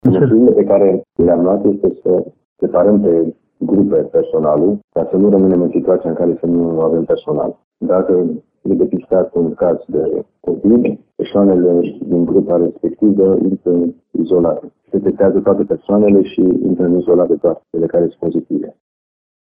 Președintele CJT, în subordinea căruia se află Direcția de Asistență Socială și pentru Protecția Copilului, spune că, deşi sunt mai multe focare active, în ultimele săptămâni, în căminele de bătrâni și copii, totuși trendul este descendent în ultima vreme.